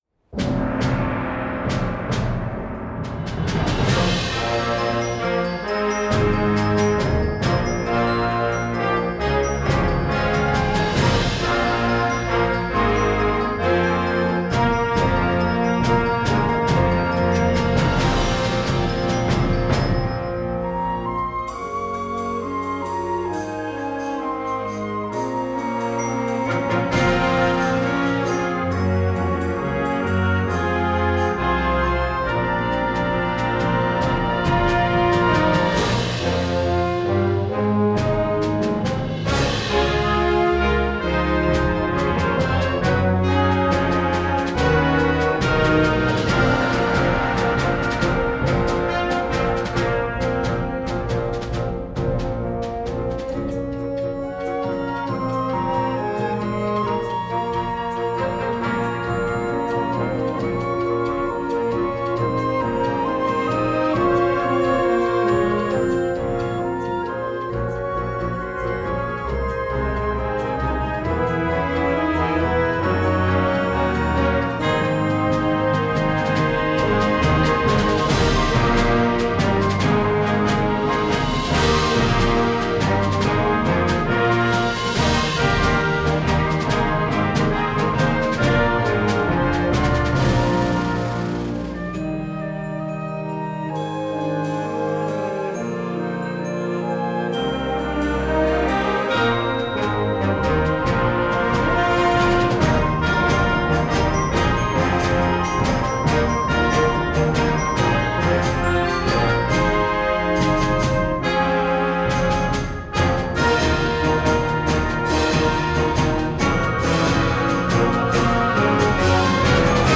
Gattung: Eröffnungswerk für Jugendblasorchester
Besetzung: Blasorchester
ist ein fanfarenartiger Konzert-Opener